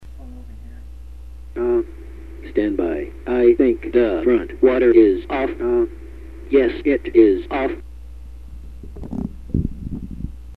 The Speech Concatenator appears to stutter at such times, and is difficult to understand.
Example of Syllable Concatenator speech.
BBALLBLU.GIF, 139B Attention: Submarine Sonar sound
BBALLBLU.GIF, 139B Clear Throat
BBALLBLU.GIF, 139B End of Message Period: Spit